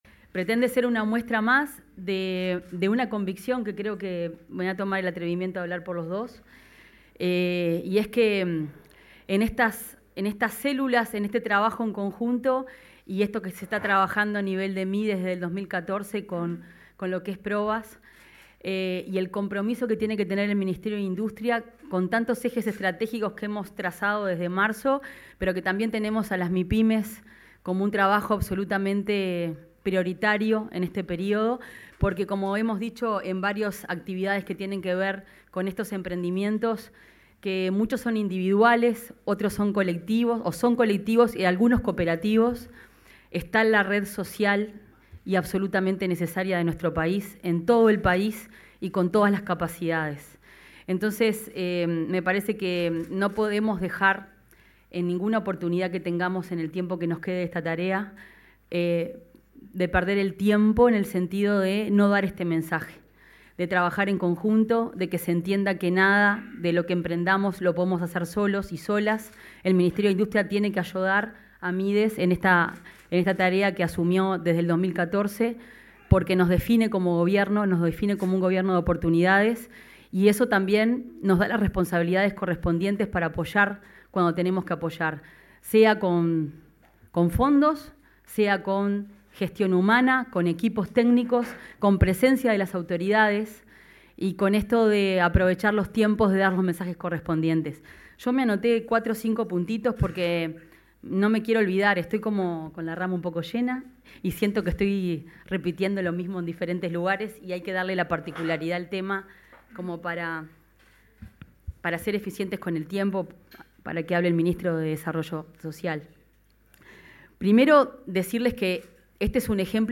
Palabras de autoridades en presentación del proceso Hacia una Estrategia Nacional Provas 2025-2030
oratoria ministra.mp3